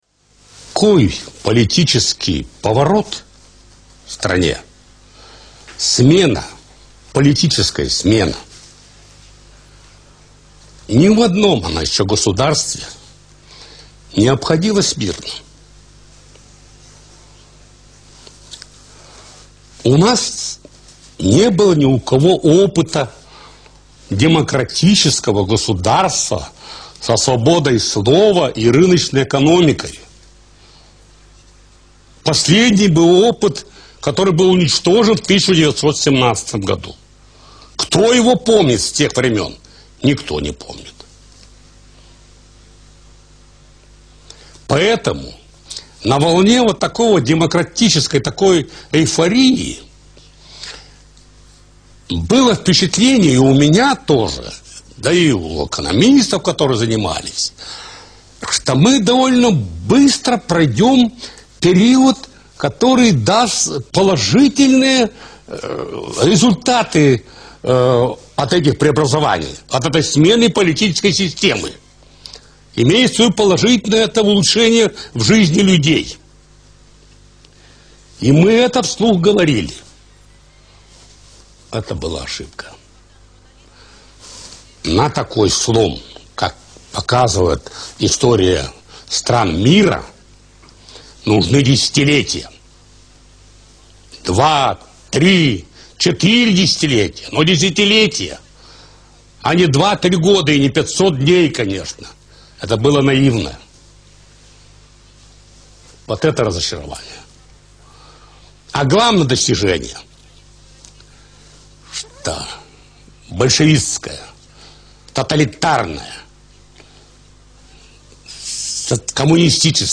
Boris Yeltsin Interview